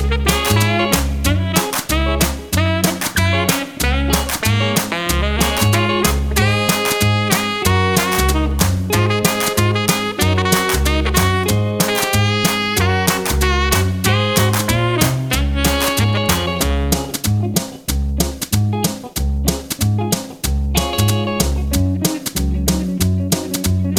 Slightly Faster Pop (1960s) 2:39 Buy £1.50